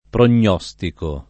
pronostico [ pron 0S tiko ]